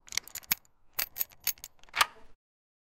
Locks Sound Effects - Free AI Generator & Downloads
unlock-keylock-sound-effe-hul7hqqo.wav